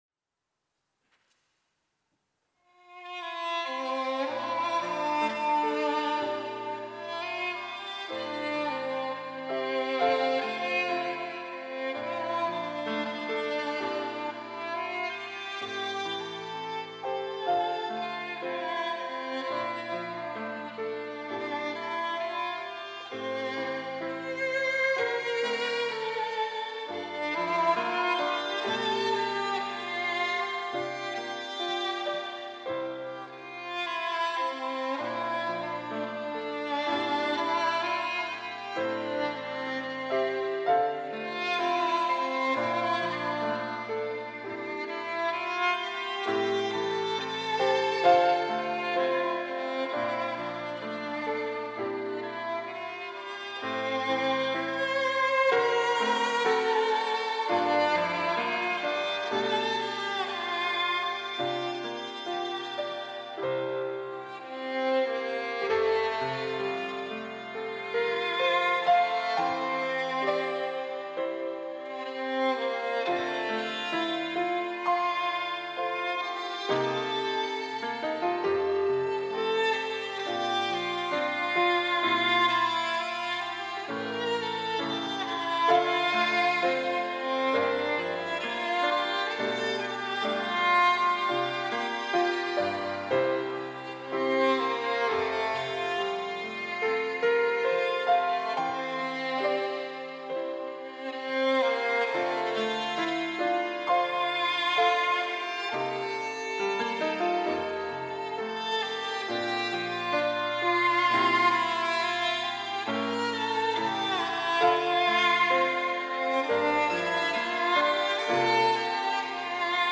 특송과 특주 - 감사하는 삶